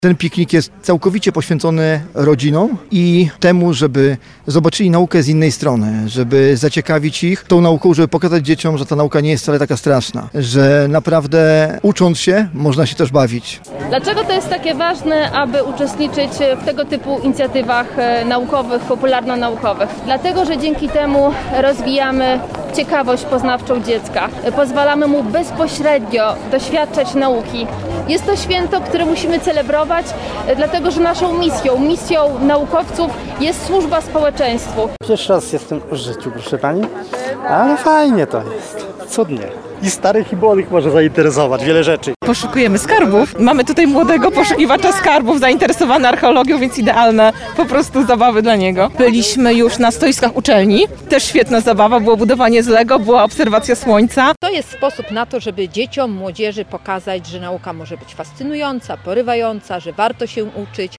O wrażeniach oraz o tym, dlaczego warto organizować tego typu akcje, mówili nam uczestnicy wydarzenia: